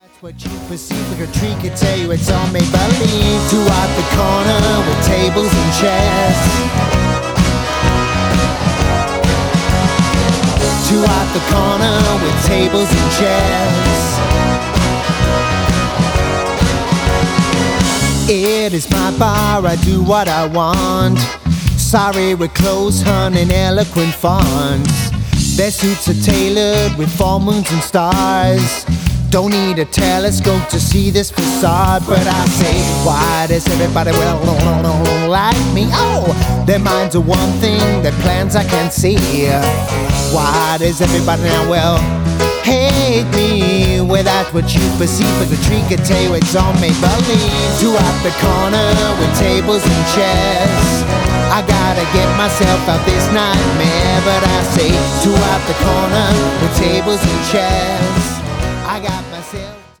reggae vom feinstem!